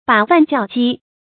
把飯叫饑 注音： ㄅㄚˇ ㄈㄢˋ ㄐㄧㄠˋ ㄐㄧ 讀音讀法： 意思解釋： 比喻多此一舉。